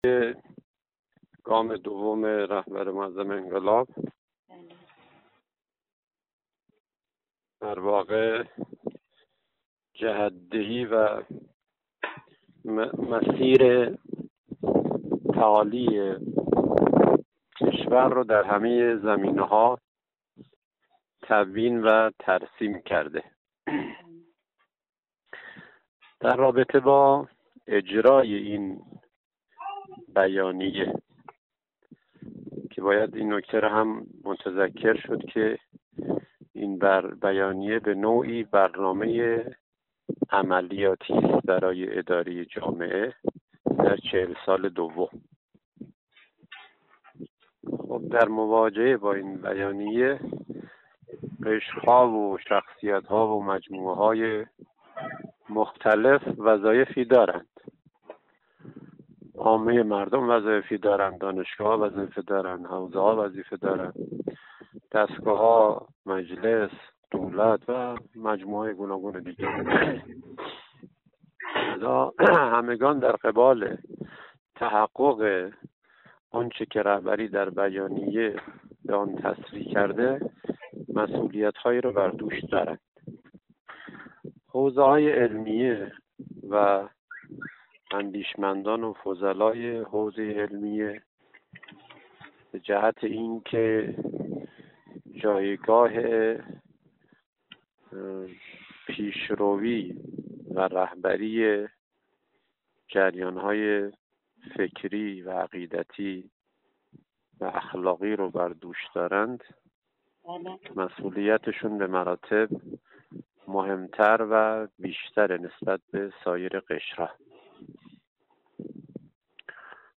در گفت و گو با خبرنگار خبرگزاری رسا در خرم آباد